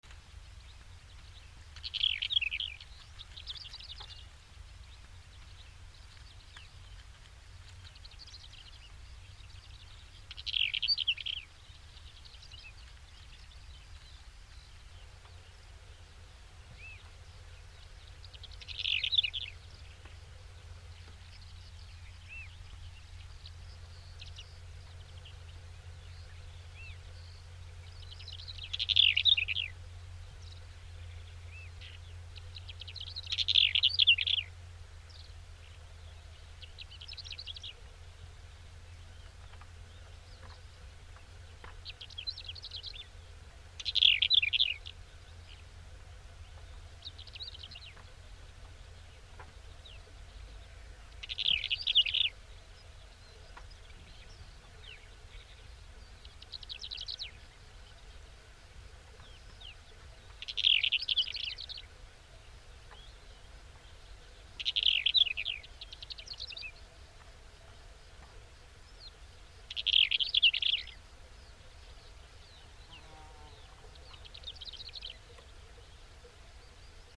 نام فارسی : چک چک گوش سیاه
نام انگلیسی : Eastern Black-eared Wheatear